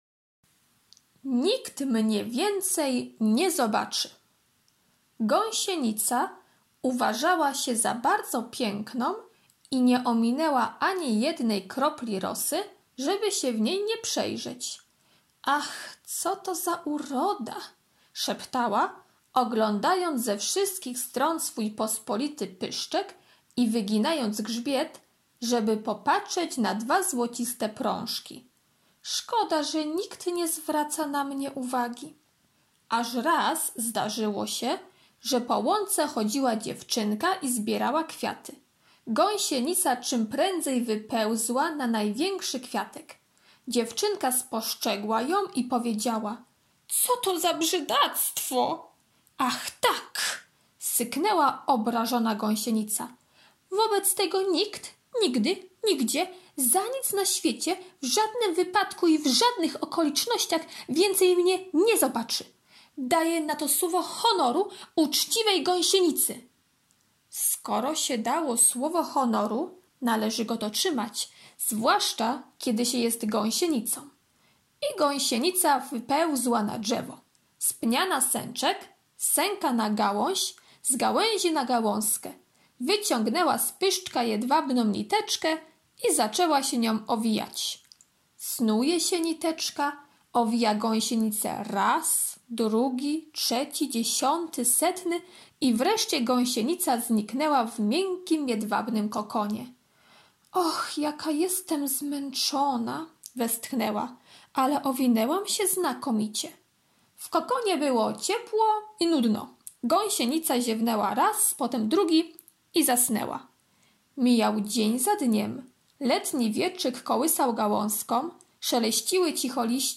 czwartek - opowiadanie "Nikt mnie więcej nie zobaczy" [7.25 MB] czwartek - prezentacja "Motyl" [1.90 MB] czwartek - ćw. dla chętnych - litera F, f [160.52 kB] czwartek - ćw. dla chętnych - karta pracy "Motyl" [250.88 kB] czwartek - ćw. dla chętnych [433.50 kB] czwartek - ćw. dla chętnych [48.21 kB]